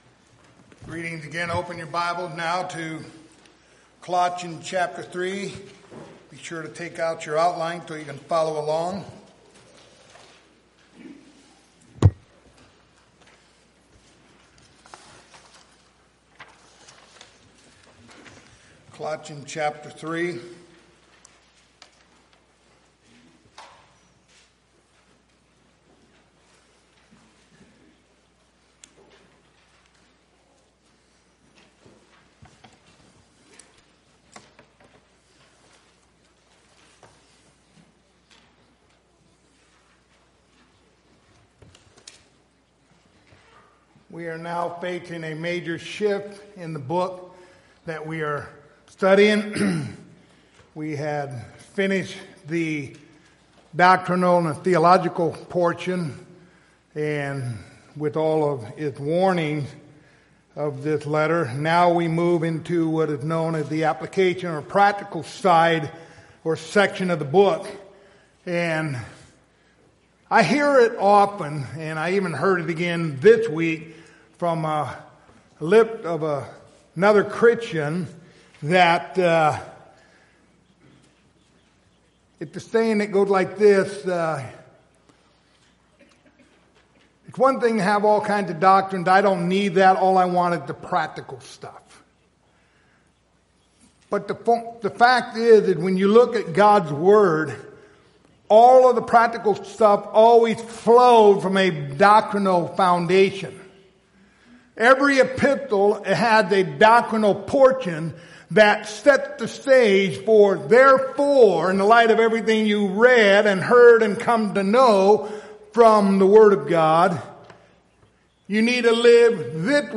Passage: Colossians 3:1-4 Service Type: Sunday Morning